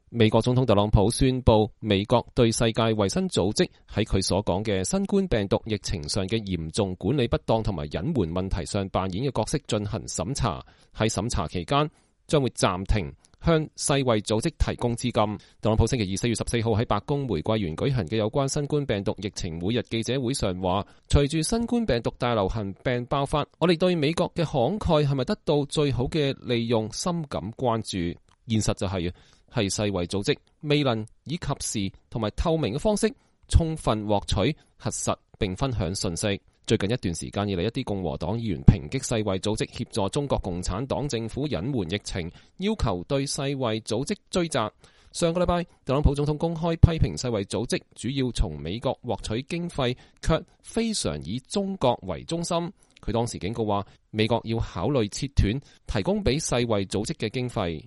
特朗普總統在白宮玫瑰園舉行的新冠病毒疫情記者會上講話。(2020年4月14日)